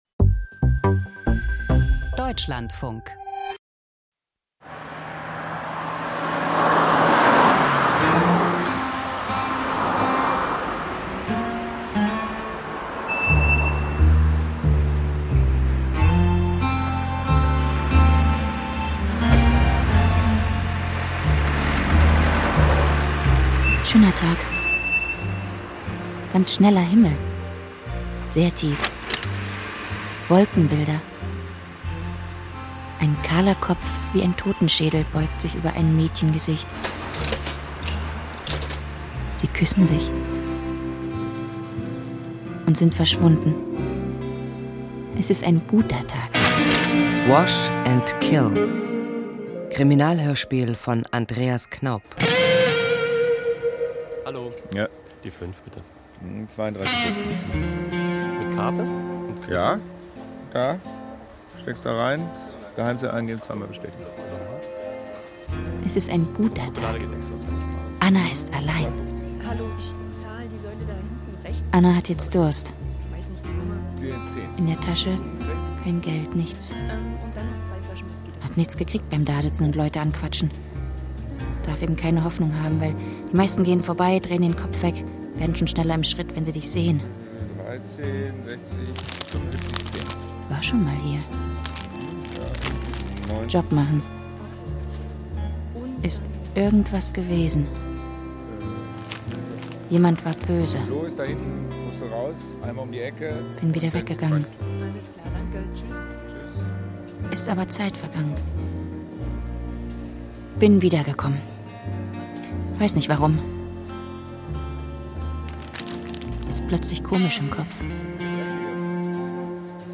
Krimi Hörspiel